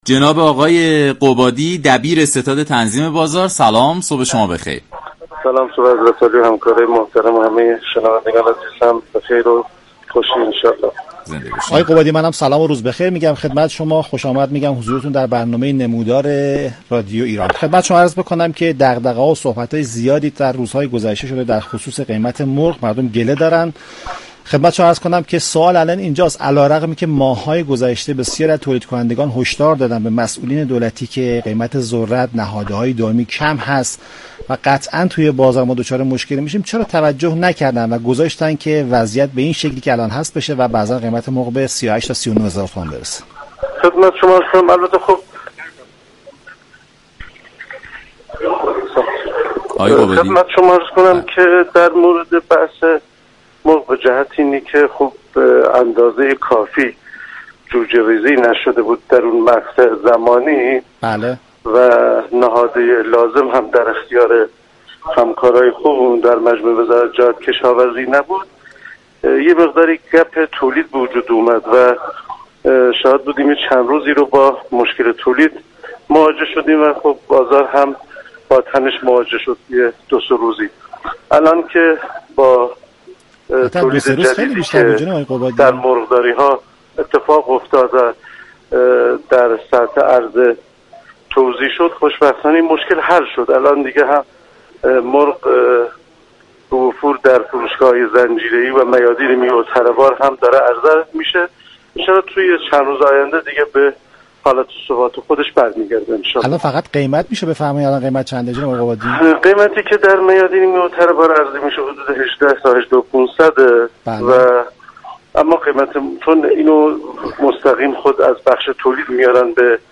به گزارش شبكه رادیویی ایران، عباس قبادی دبیر كارگروه ستاد تنظیم بازار در برنامه نمودار درباره گرانی و قیمت بالای مرغ گفت: كمبود نهاده های دامی و كافی نبودن میزان جوجه ریزی، تولید مرغ را در كشور دچار مشكل كرد.